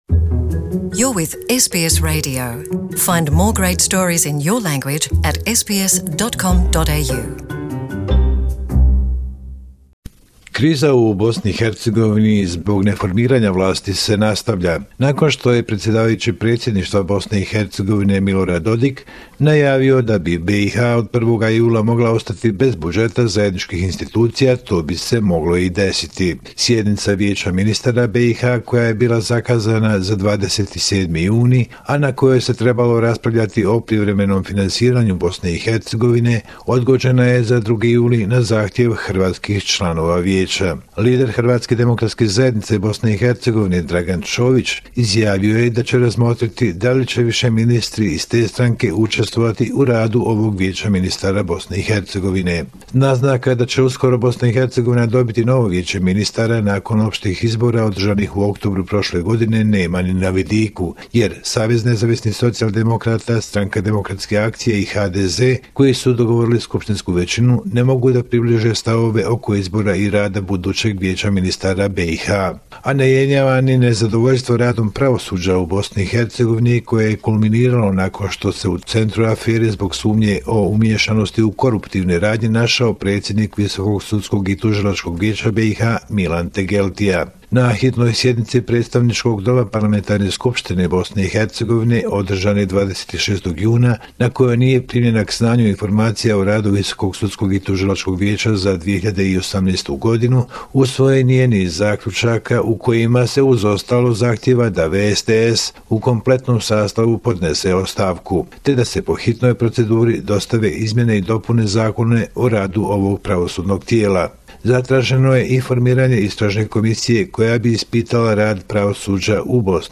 Bosnia and Herzegovina - affairs in the country for the last seven day, weekly report June 30, 2019